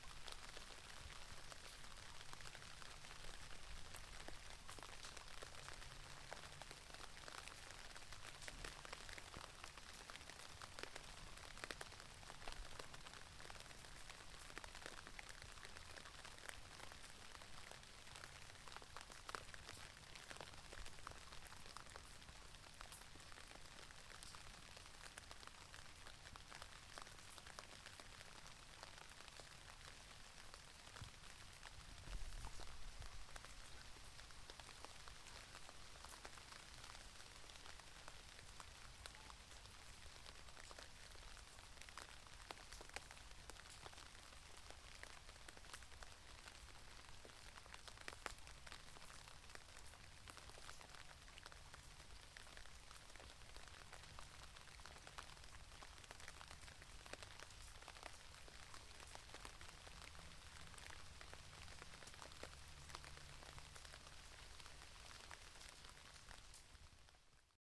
充气式床垫上的雨水
描述：小雨落在沙滩充气床垫上。C1000进入Fostex FR2.
Tag: 充气床垫 自然 铁板 雨水